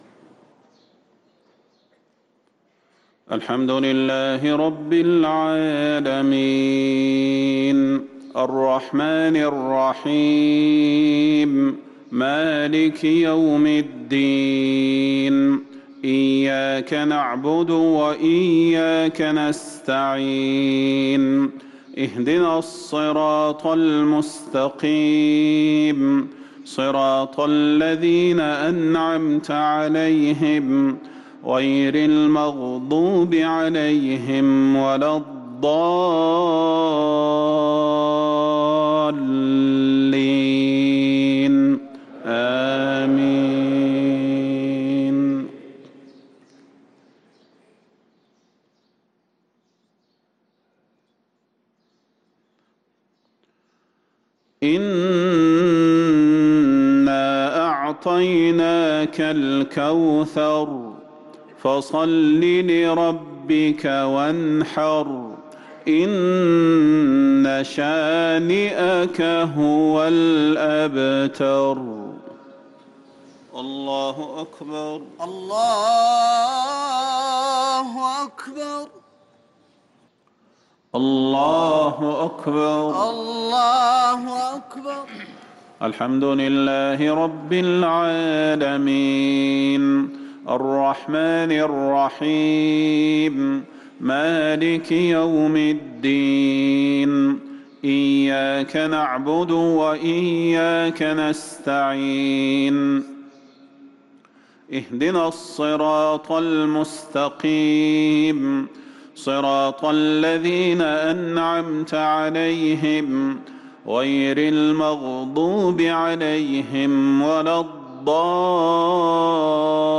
صلاة المغرب للقارئ صلاح البدير 25 شعبان 1444 هـ